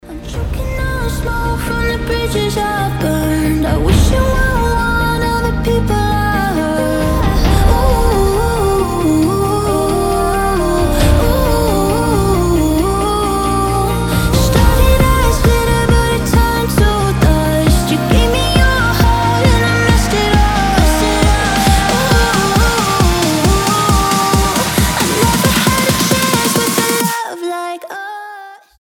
• Качество: 320, Stereo
мелодичные
Electronic
нарастающие
красивый женский голос
future bass